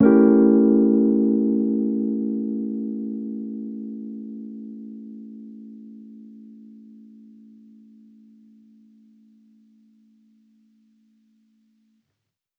Index of /musicradar/jazz-keys-samples/Chord Hits/Electric Piano 1
JK_ElPiano1_Chord-A7b9.wav